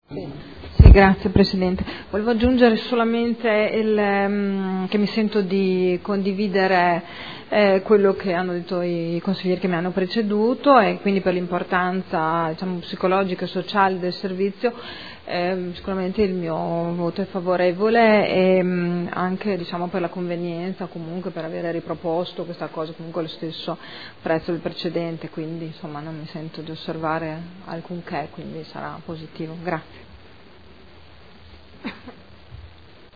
Sandra Poppi — Sito Audio Consiglio Comunale
Seduta del 05/11/2012. Dibattito su proposta di deliberazione: Linee di indirizzo per l’affidamento del servizio di trasporto disabili alle attività diurne, socio-occupazionali e del tempo libero – Periodo dal 1.3.2013 al 28.2.2015